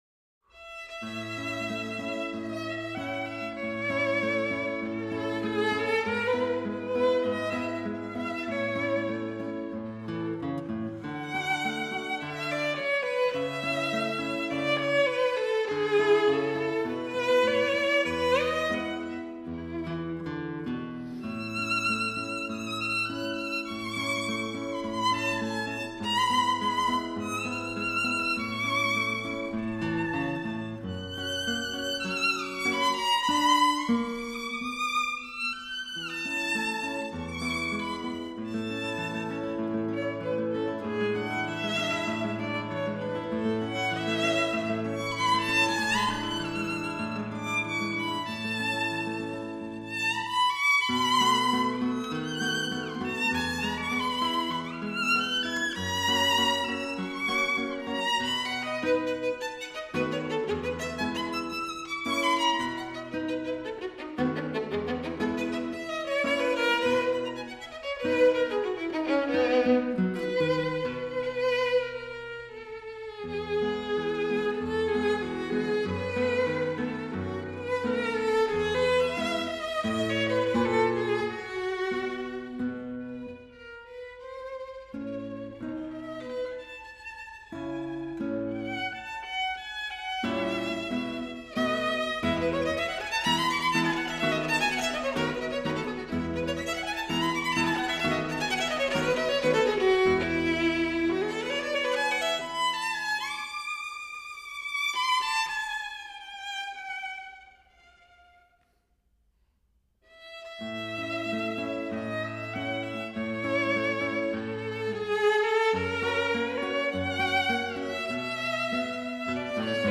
室内乐